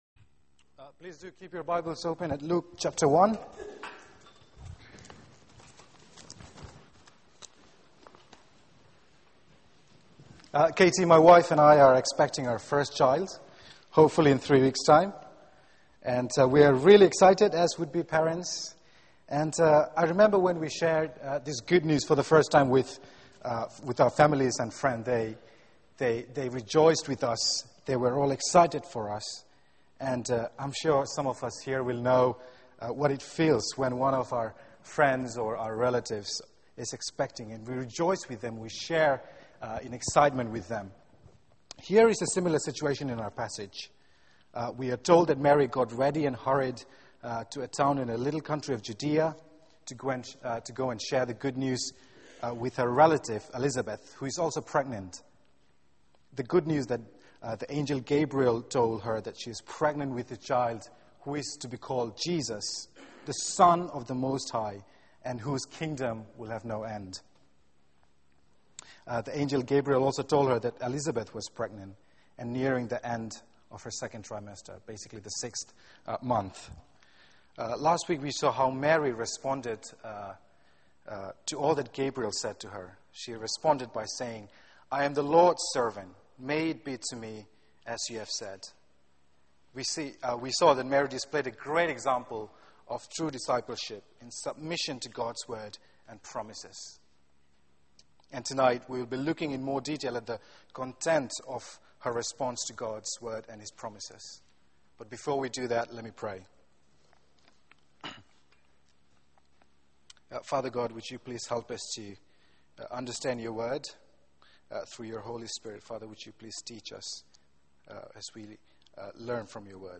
Media for 6:30pm Service on Sun 25th Nov 2012
Theme: An antenatal group with a difference Sermon